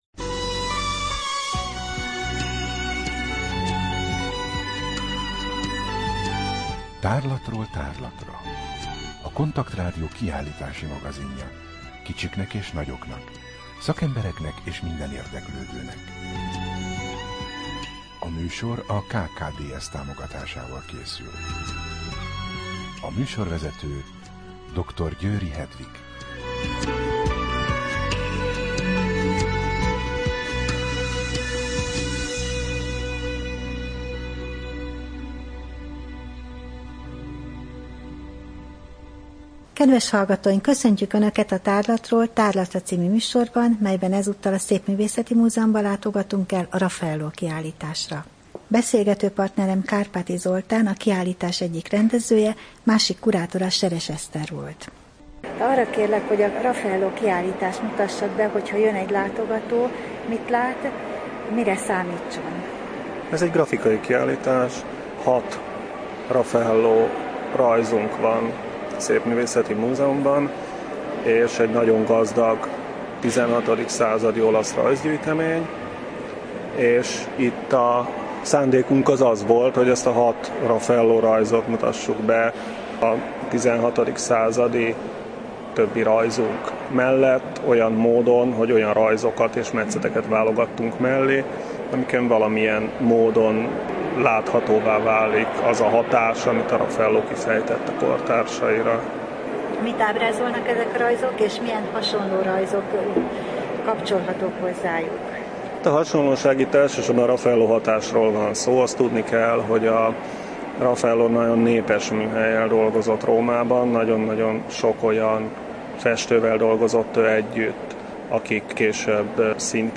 Rádió: Tárlatról tárlatra Adás dátuma: 2014, Január 13 Tárlatról tárlatra / KONTAKT Rádió (87,6 MHz) 2014 január 13. A műsor felépítése: I. Kaleidoszkóp / kiállítási hírek II. Bemutatjuk / Az eszmény diadala – Raffaelló, Szépművészeti Múzeum A műsor vendége